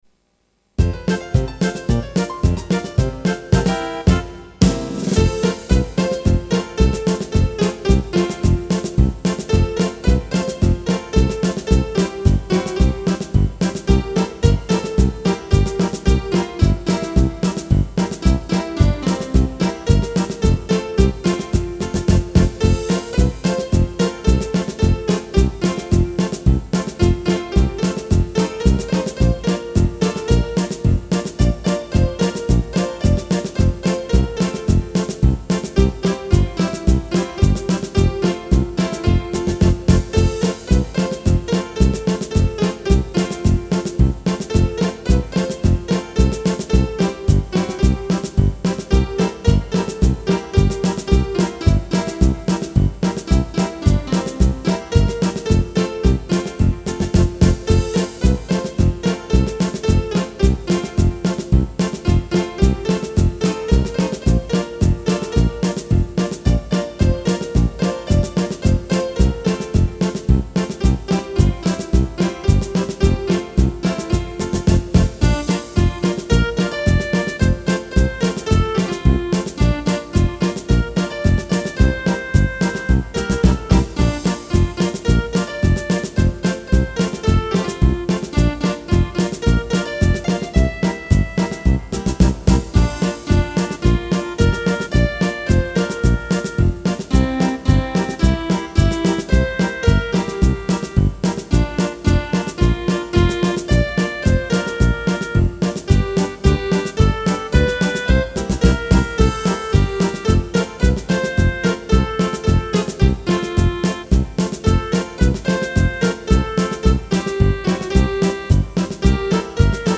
Ragtime